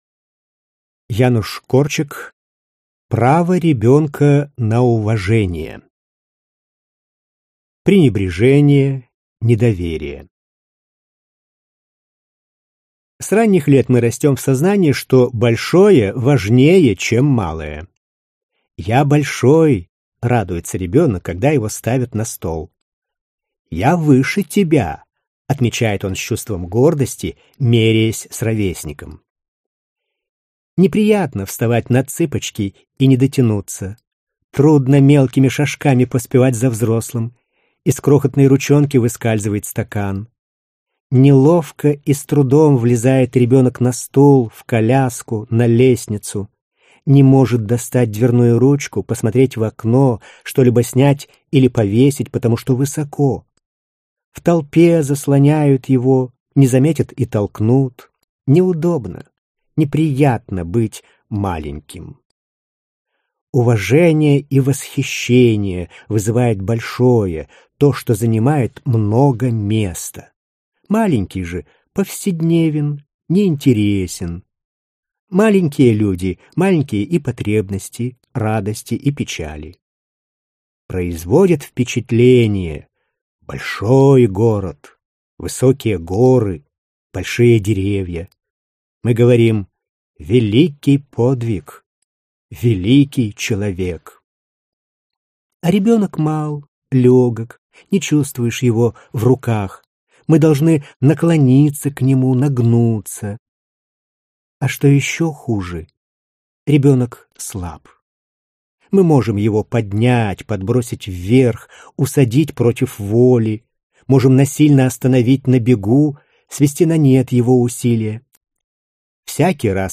Аудиокнига Право ребенка на уважение | Библиотека аудиокниг